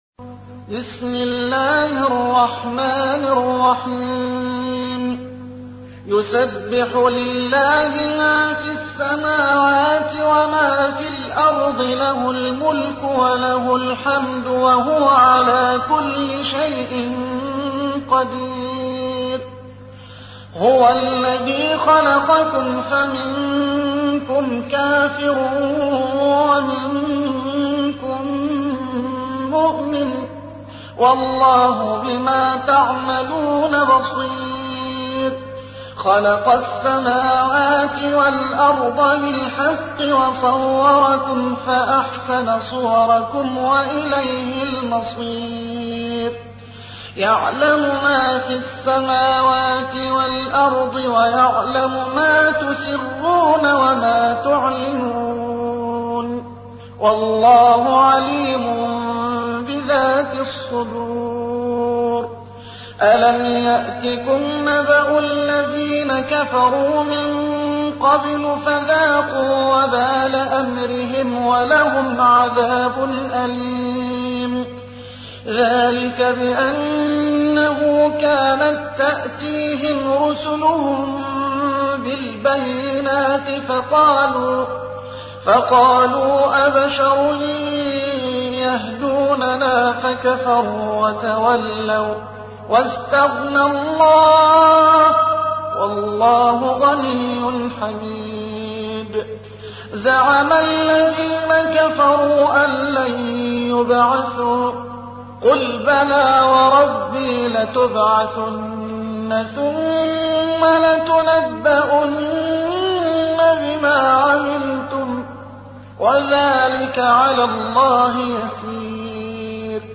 64. سورة التغابن / القارئ